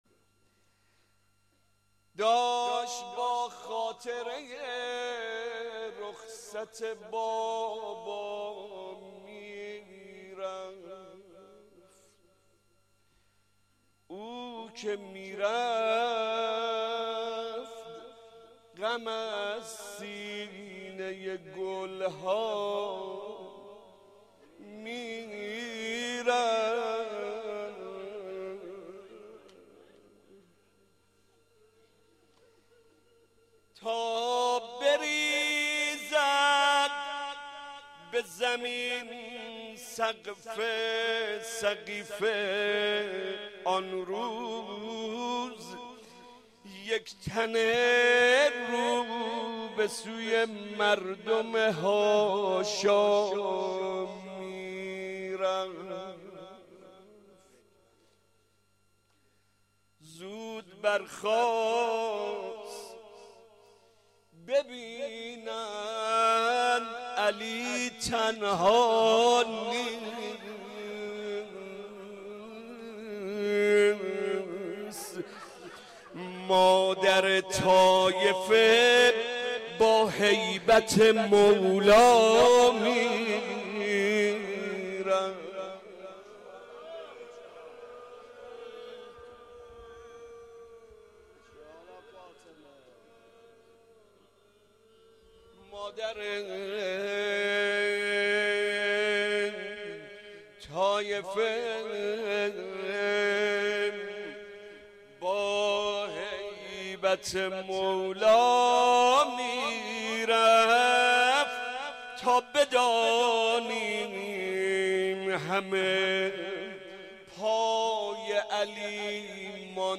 جهت دریافت مراسم " شب اول فاطمیه اول هیات مکتب الزهرا " به ادامه ی مطلب مراجعه نمایید
مرثیه خوانی
روضه حضرت زهرا